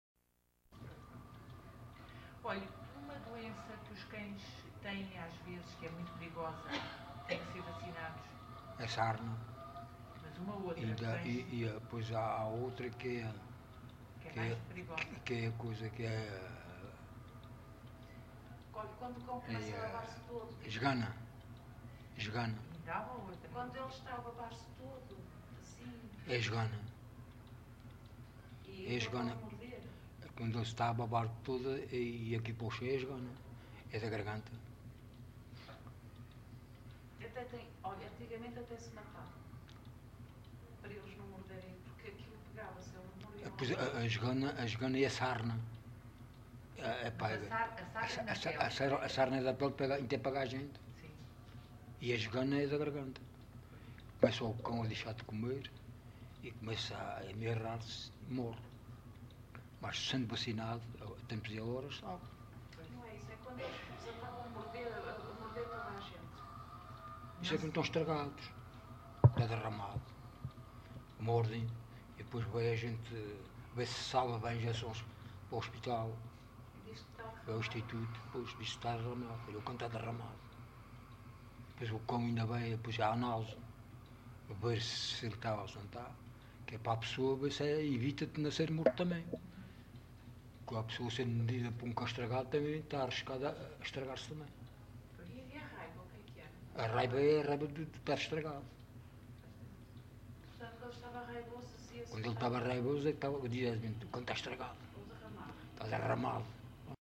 LocalidadeAlcochete (Alcochete, Setúbal)